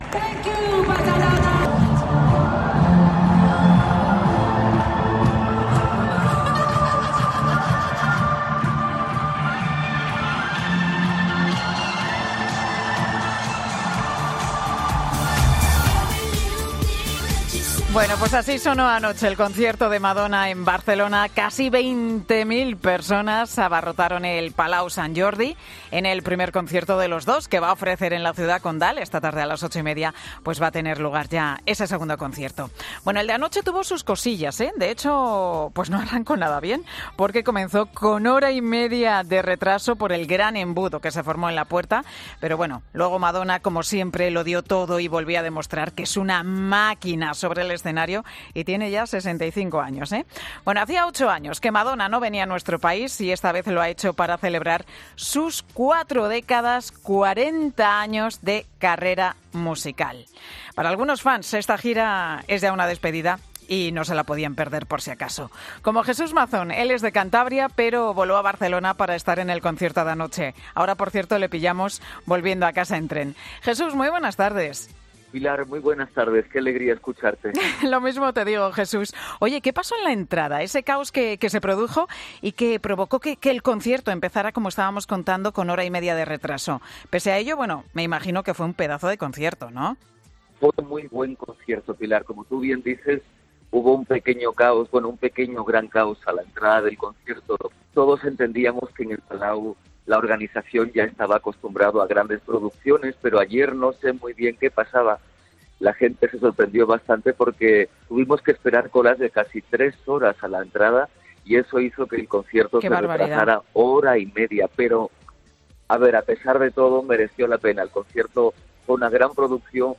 Un aficionado de Madonna cuenta qué sucedió para que se retrasara una hora y media el concierto